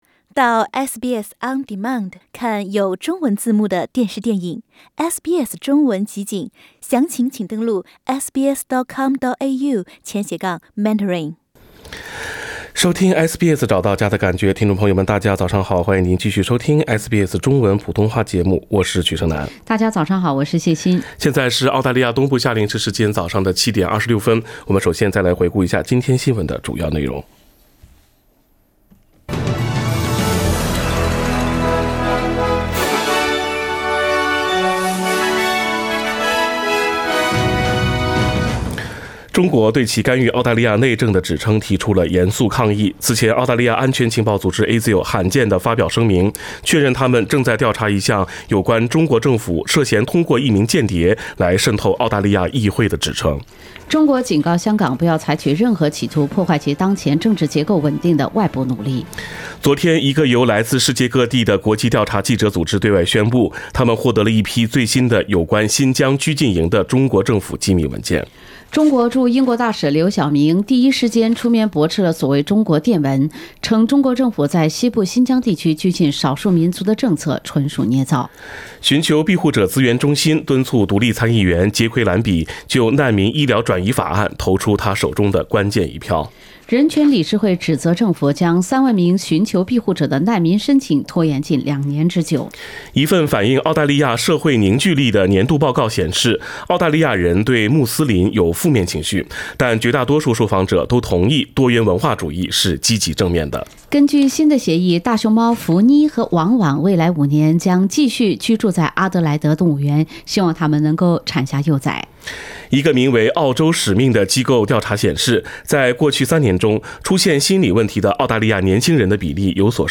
SBS Chinese Morning News Source: Shutterstock